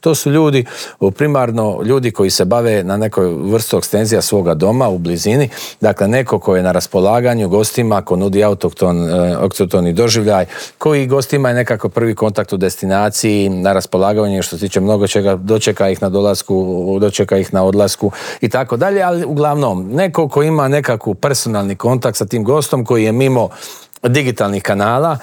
O dosad ostvarenim rezultatima, očekivanjima od ljetne turističke sezone, ali i o cijenama smještaja te ugostiteljskih usluga razgovarali smo u Intervjuu tjedna Media servisa s ministriom turizma i sporta Tončijem Glavinom.